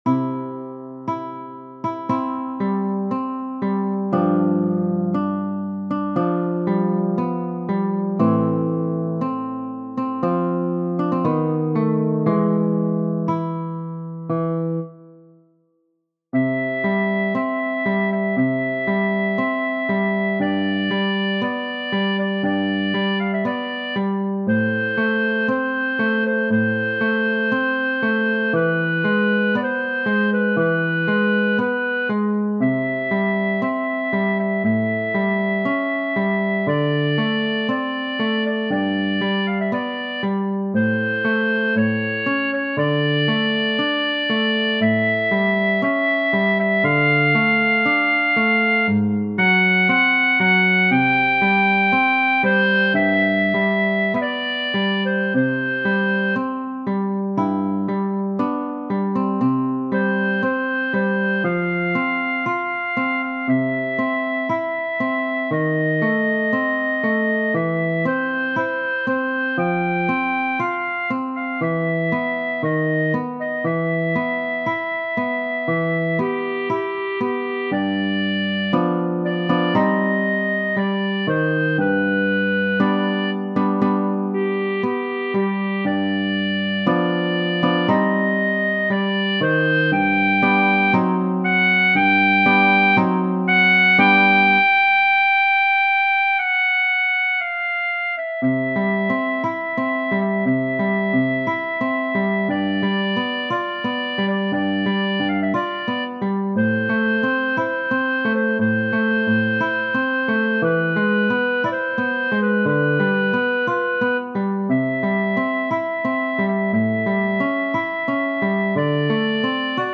Donizetti, G. Genere: Opera Testo originale francese di A. Royer/G. Vaez Ange si pur, que dans un songe j'ai cru trouver, vous que j'aimais!